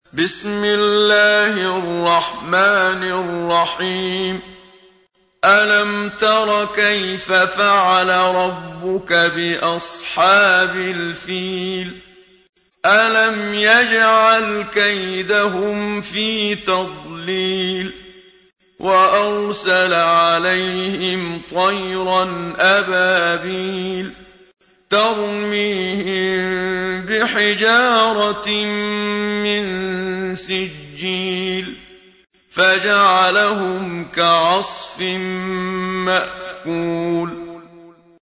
تحميل : 105. سورة الفيل / القارئ محمد صديق المنشاوي / القرآن الكريم / موقع يا حسين